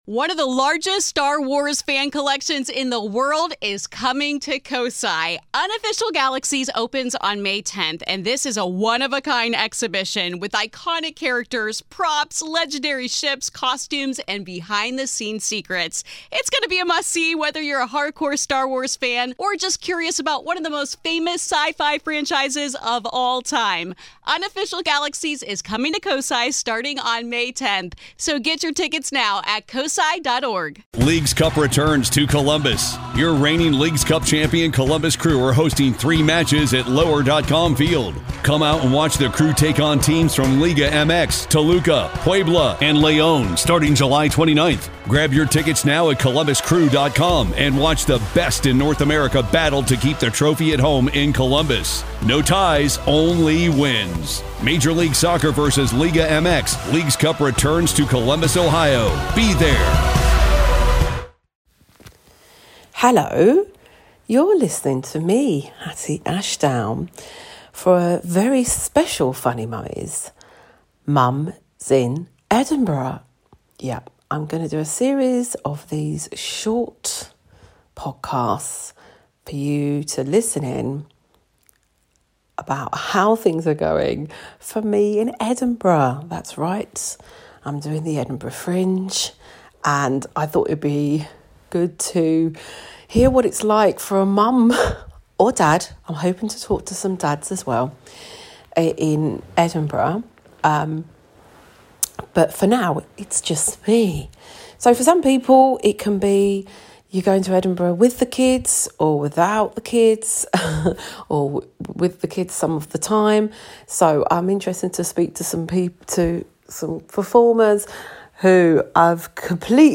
we are a doing a series of short pods, more like voice notes